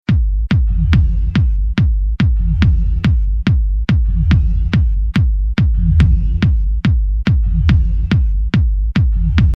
fan remix / friendly bootleg